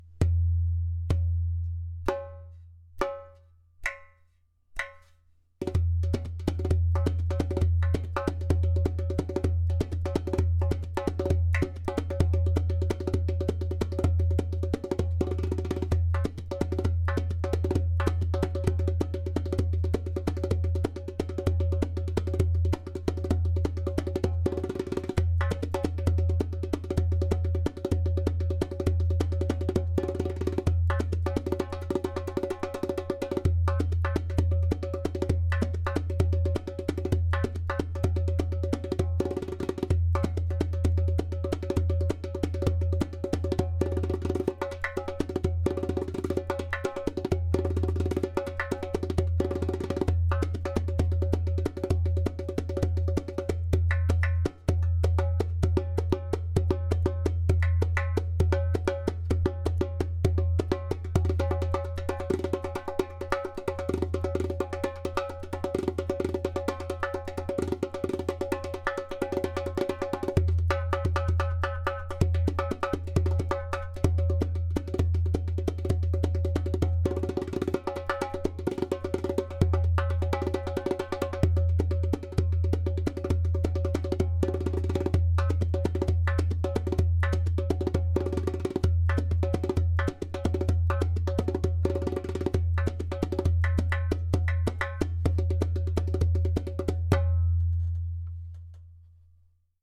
130bpm
• Strong and super easy to produce clay kik (click) sound
• Deep bass
• Even tonality around edges.
• Beautiful harmonic overtones.
• Skin: Fish skin